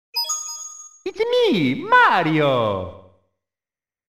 Tesla Lock Sounds & Chimes Collection: Movies, Games & More - TeslaMagz